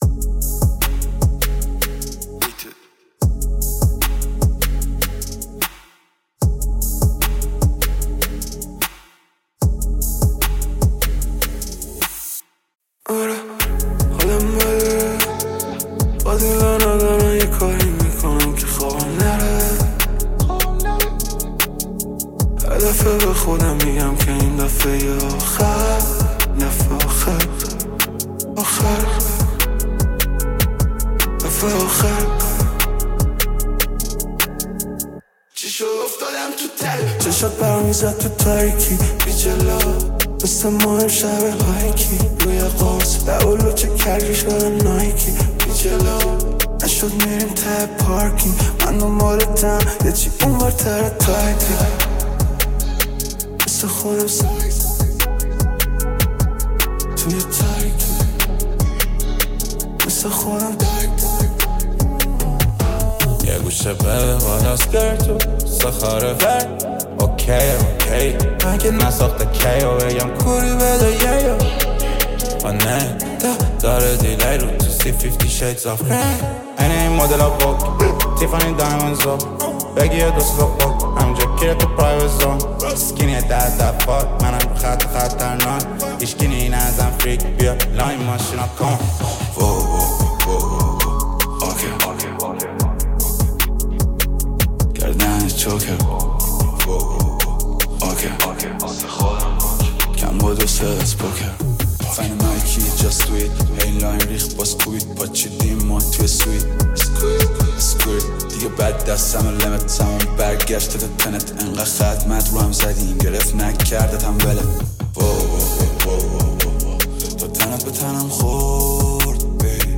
یکی از ترک‌های پرانرژی و مدرن رپ فارسی است
با بیت خاص و فلوی حرفه‌ای